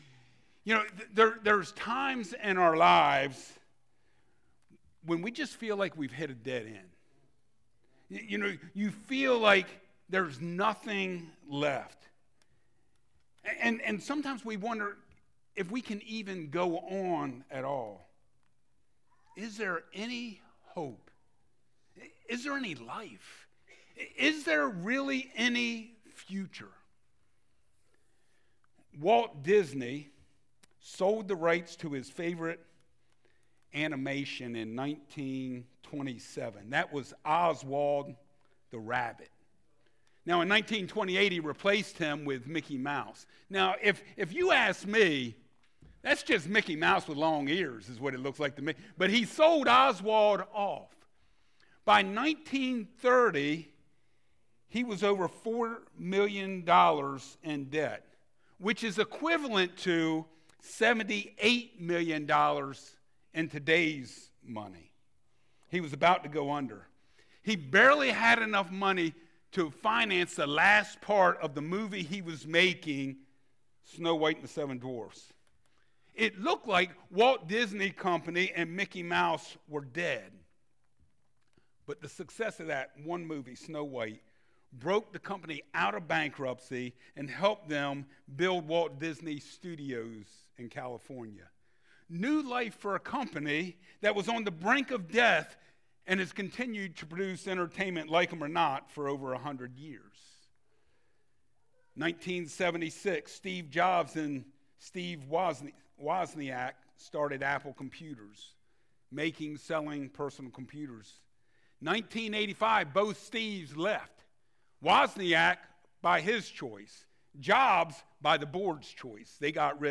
Passage: Isaiah 11:1-10 Service Type: Sunday Mornings Wait For It…